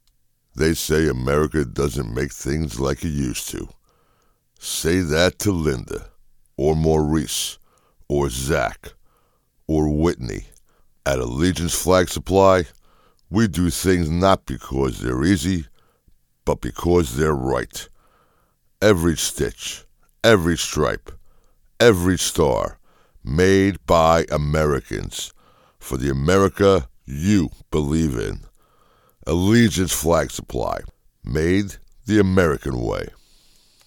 Voice is Deep, Distinctive, and Authoritative, with a Commanding Presence that exudes Confidence and Power.
Radio Commercials